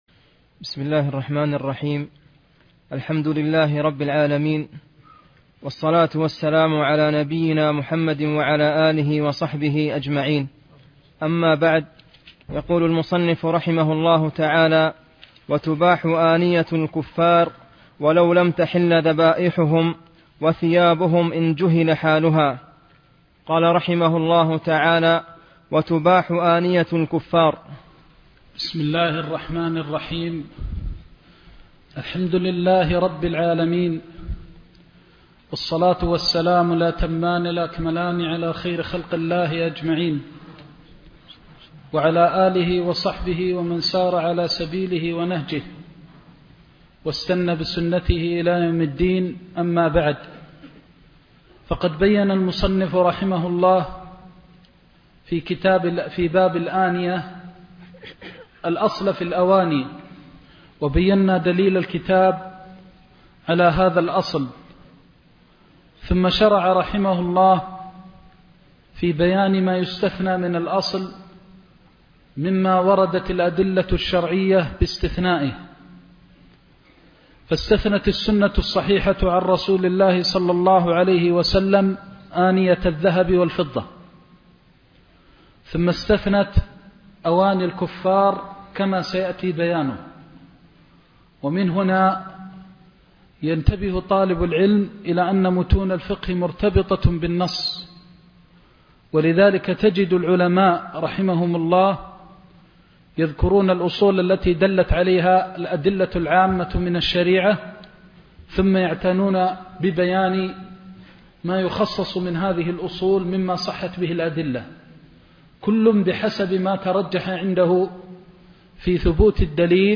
زاد المستقنع كتاب الطهارة (9) درس مكة - الشيخ محمد بن محمد المختار الشنقيطي